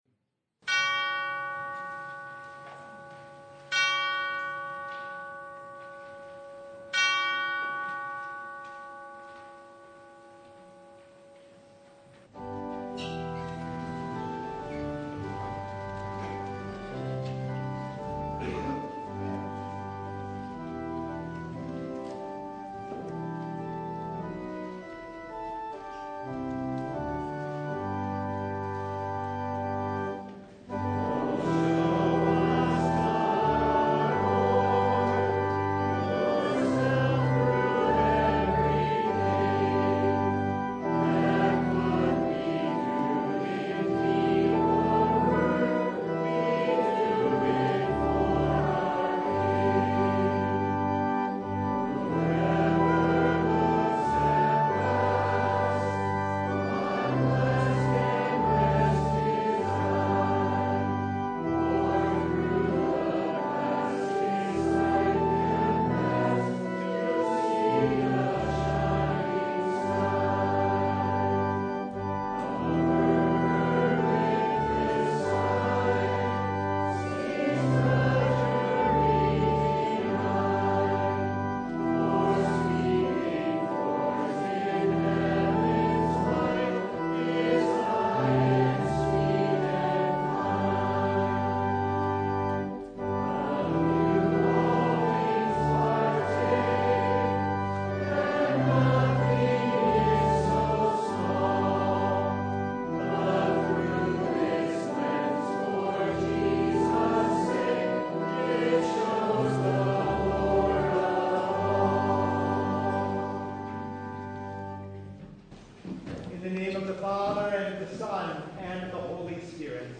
Philemon 1:1-21 Service Type: Sunday A runaway slave